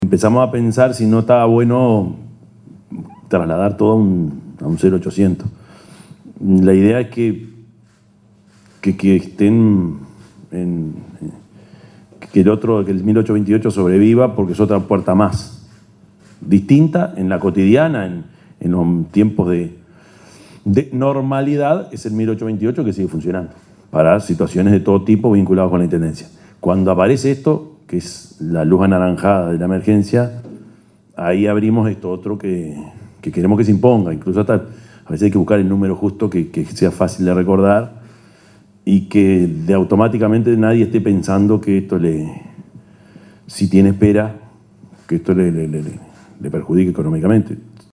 Intendente de Canelones, Yamandú Orsi, en el lanzamiento de la línea gratuita de atención telefónica ante emergencias climáticas
yamandu_orsi_intendente_de_canelones.mp3